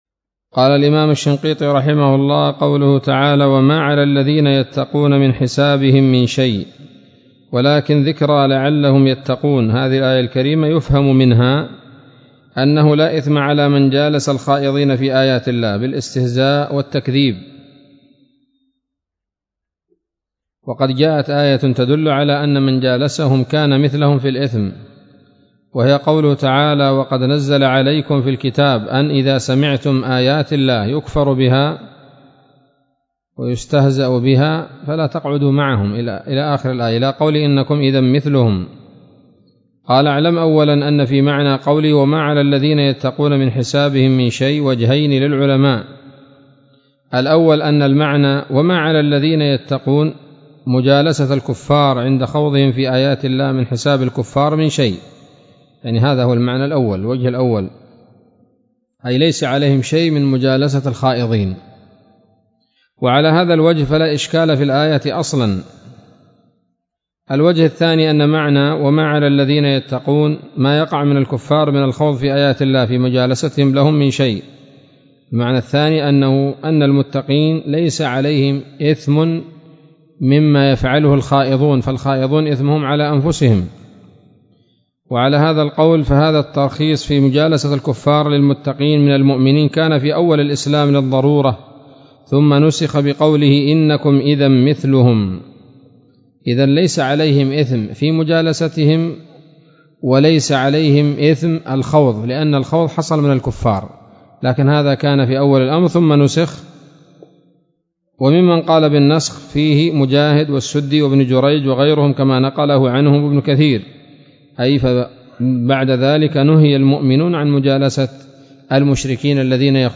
الدرس الأربعون من دفع إيهام الاضطراب عن آيات الكتاب